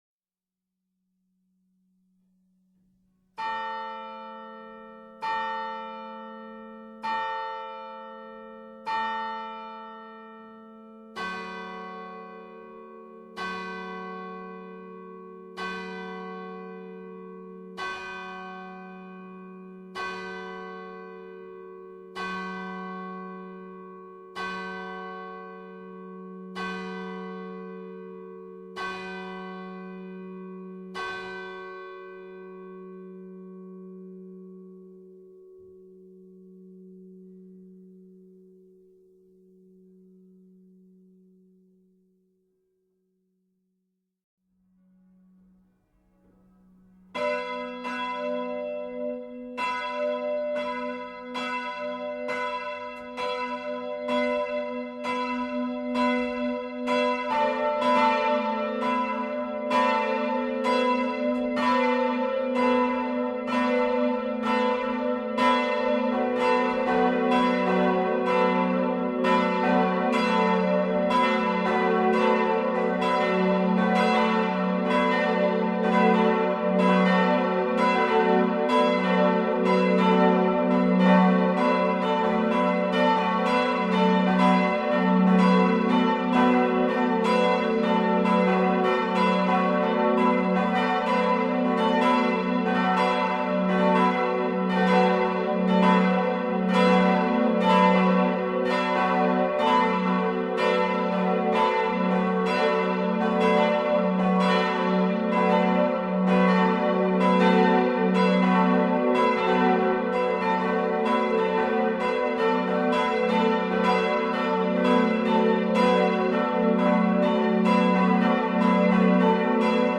Das Geläut
Das Geläut besteht aus drei Glocken, die den drei Aposteln Petrus, Jakobus und Johannes gewidmet sind.
Evang.-St.-Jakobskirche-Vollgelaeut.mp3